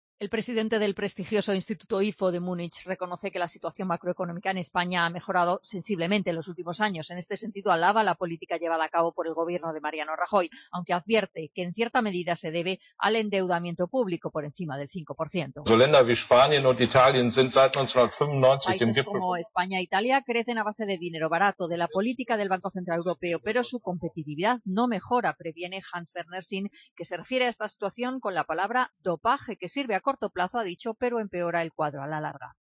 Escucha la crónica la corresponsal en Berlín